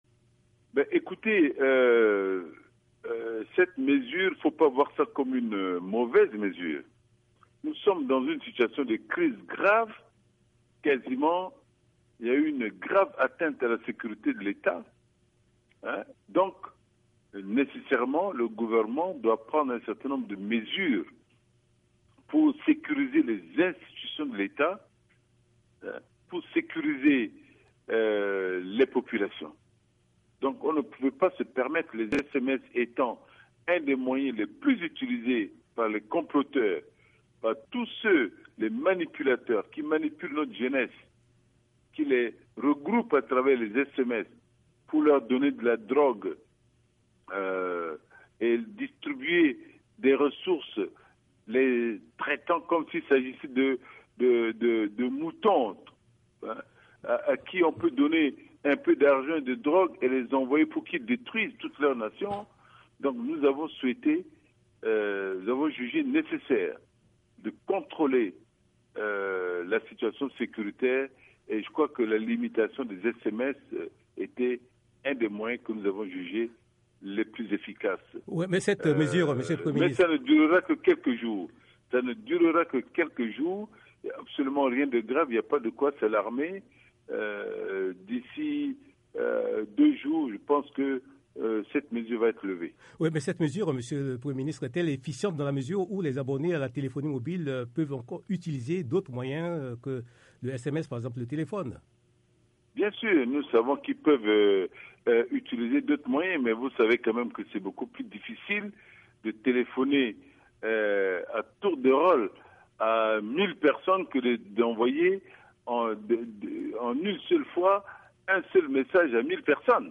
C’est ce qu’a affirmé mercredi le Premier ministre centrafricain de la transition, André Nzapayeké, dans une interview à la VOA.